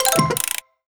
Robot Fixed.wav